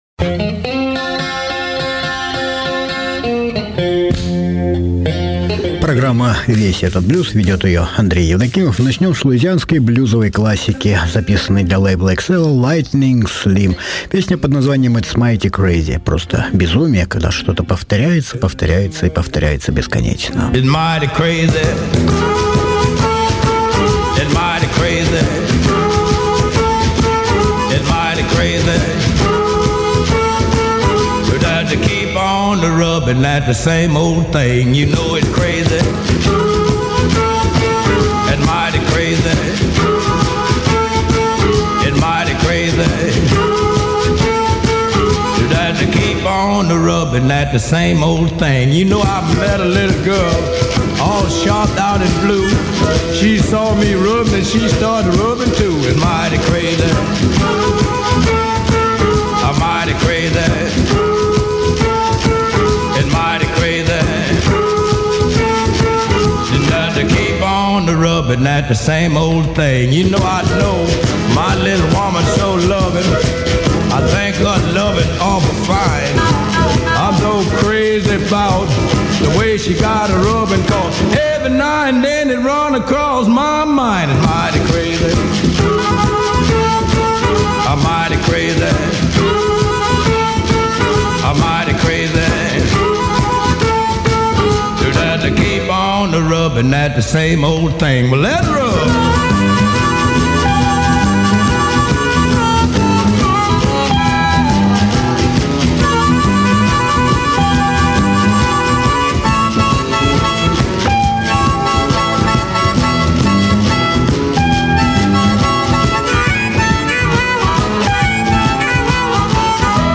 Весь этот блюз. 24.03.mp3